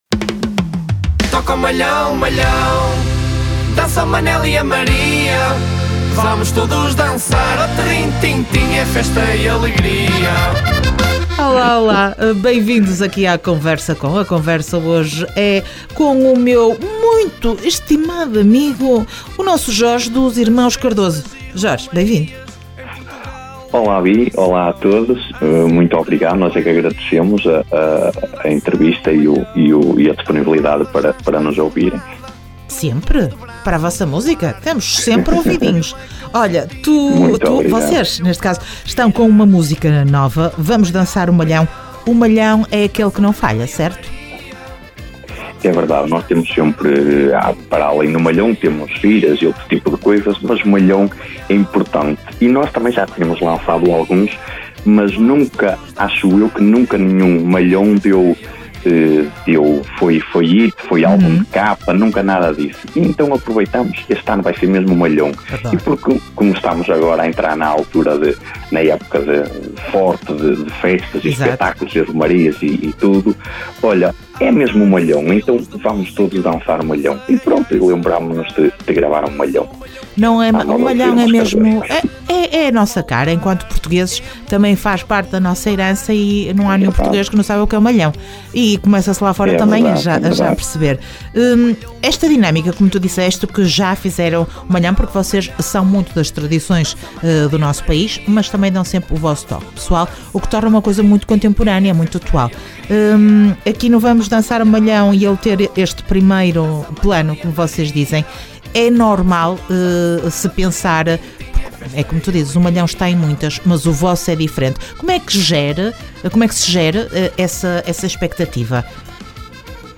Entrevista aos Irmãos Cardoso dia 22 de Abril.